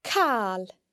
It can also be heard in càil (anything):